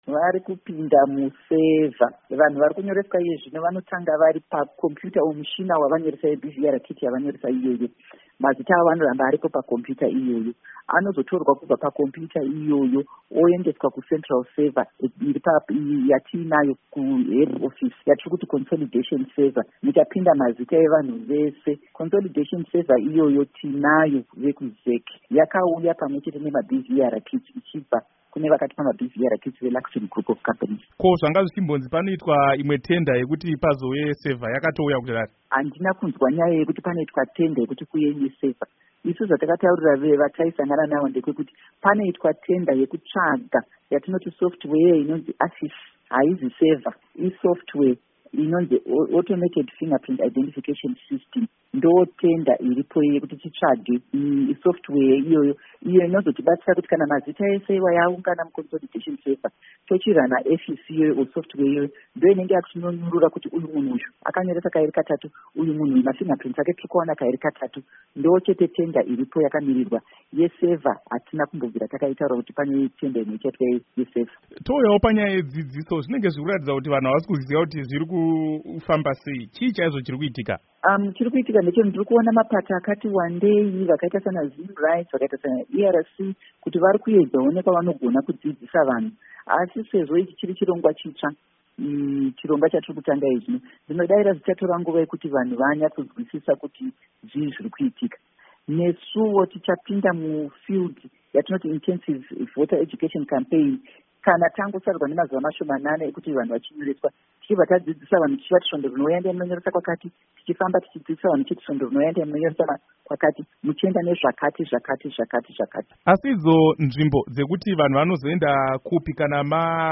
Hurukuro naAmai Rita Makarau